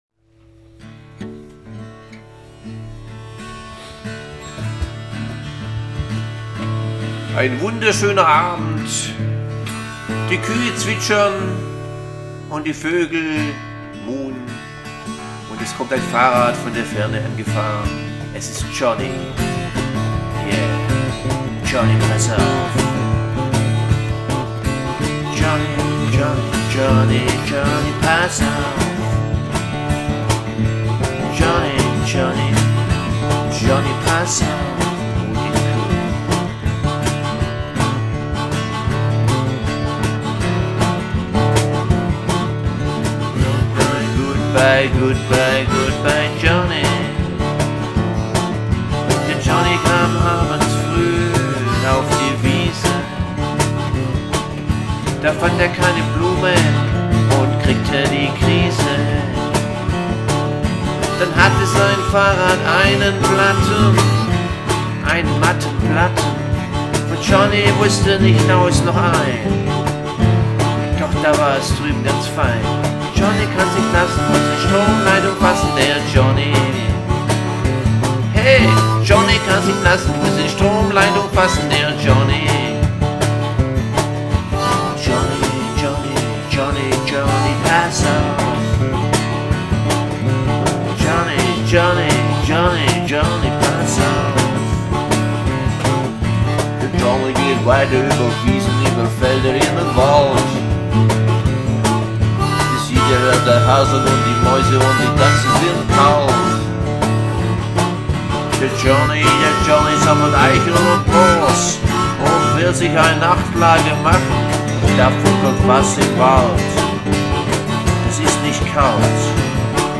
Länge: 6:23 / Tempo: 65 bpm / Datum: 11.04.2016